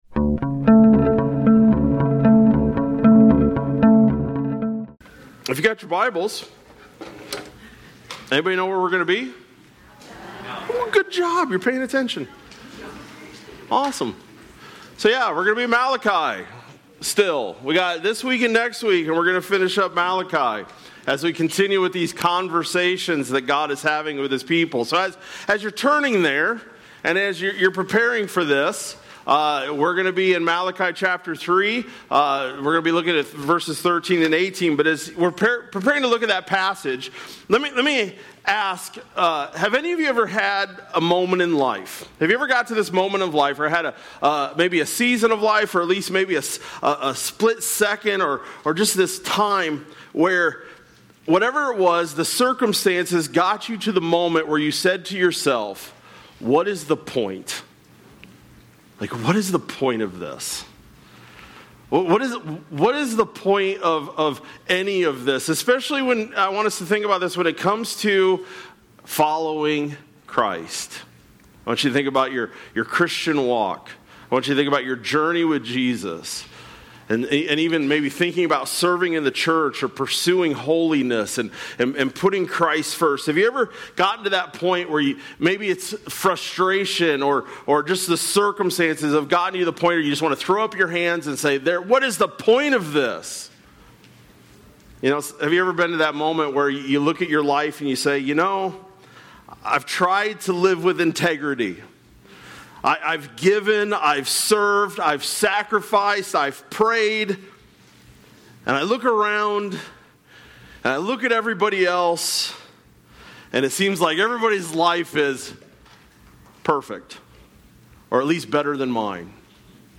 July-27-25-Sermon-Audio.mp3